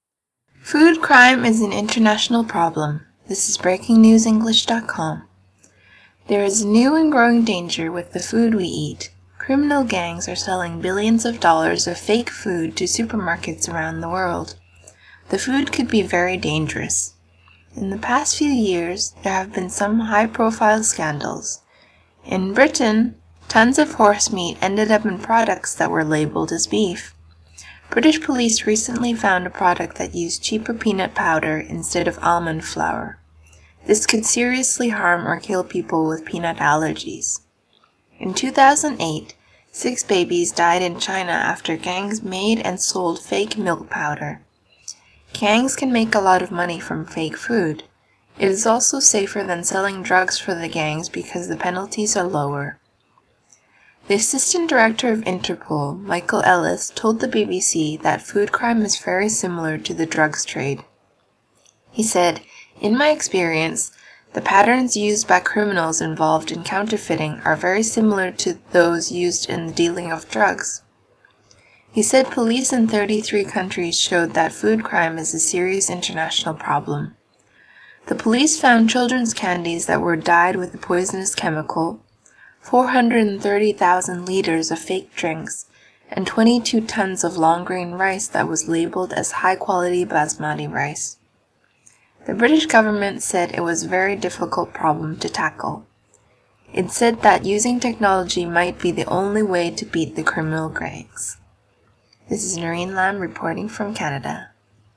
Food Crime - Listen to the story and complete your worksheet. You can listen to a British speaker, or a North American speaker.
British